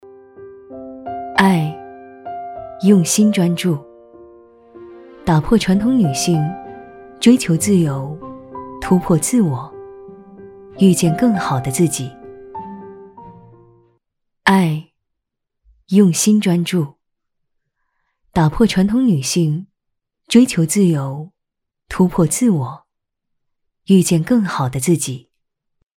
女62-独白-爱demo
女62年轻温柔 v62
女62-独白-爱demo.mp3